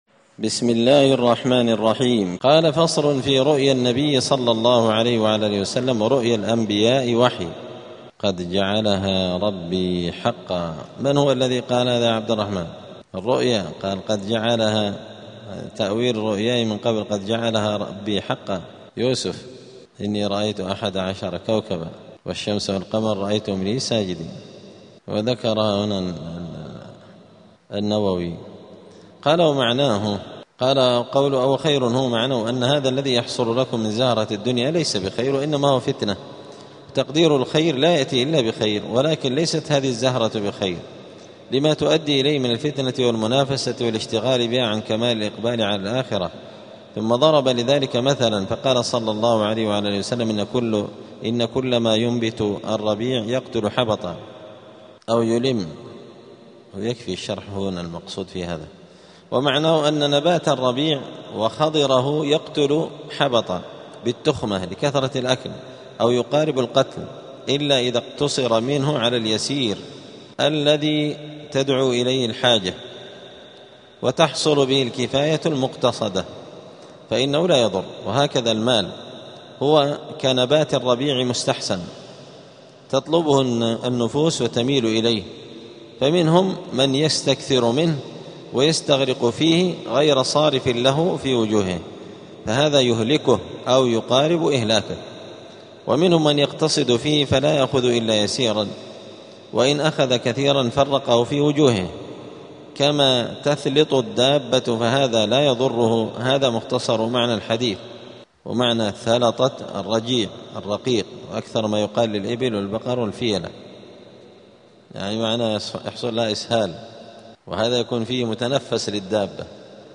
دار الحديث السلفية بمسجد الفرقان قشن المهرة اليمن 📌الدروس الأسبوعية